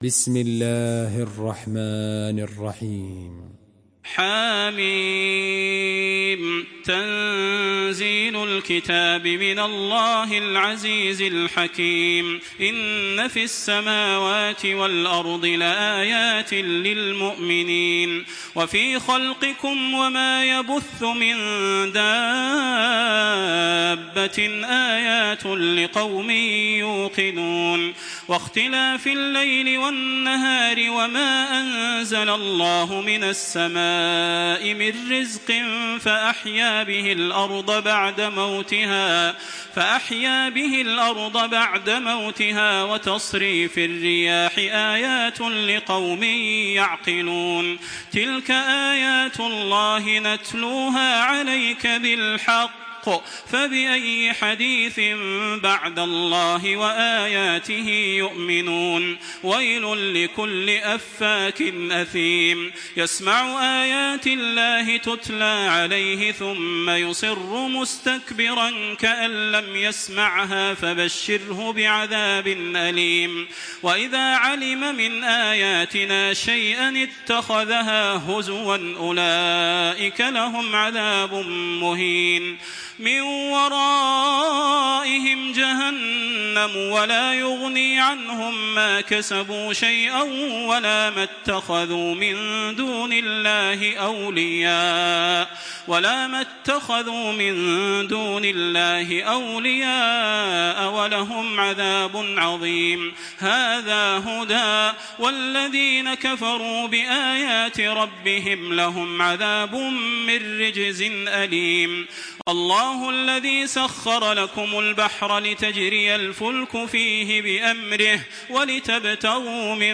تراويح الحرم المكي 1426
مرتل